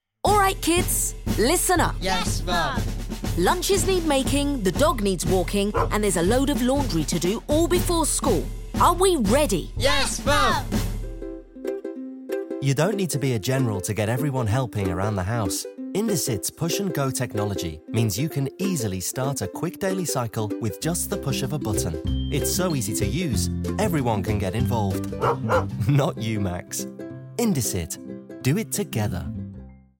30/40's London, Likeable/Upbeat/Confident
Commercial Showreel Adobe Anthem